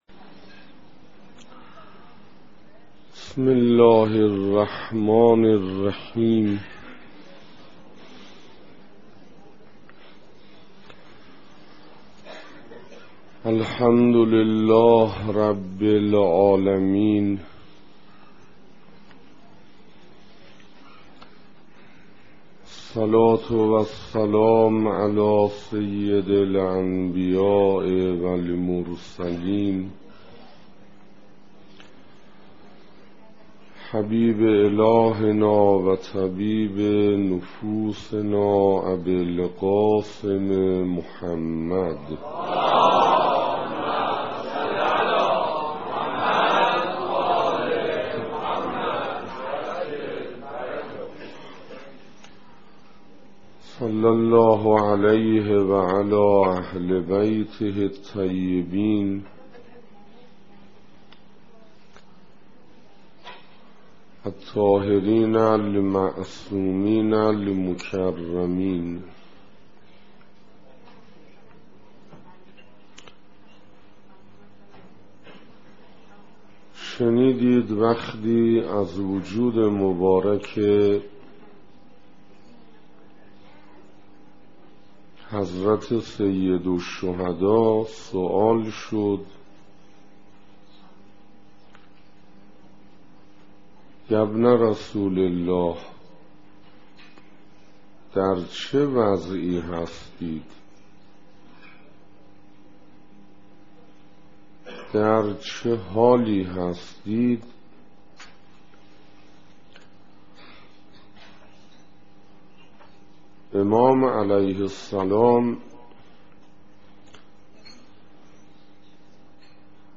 نگاه قرآن به آتش دوزخ - سخنراني سوم - محرم 1428 - مسجد انصار -